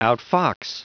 Prononciation du mot outfox en anglais (fichier audio)
Prononciation du mot : outfox